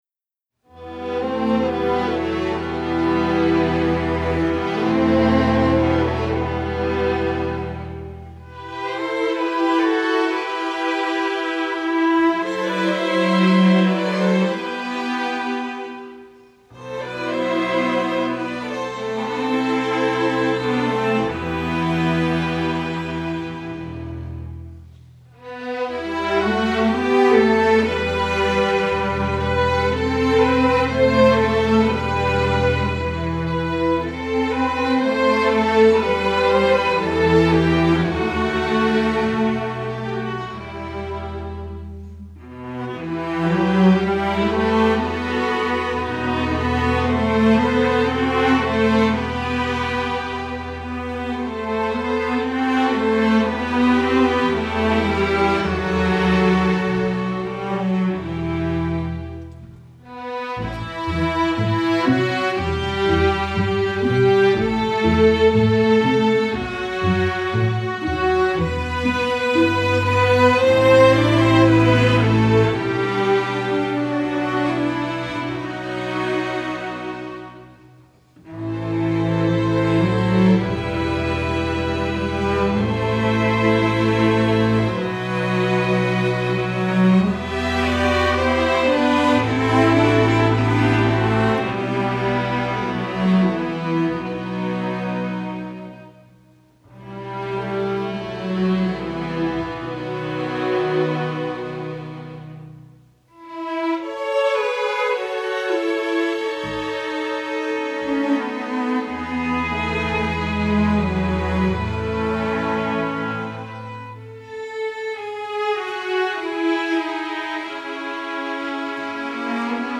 masterwork arrangement, french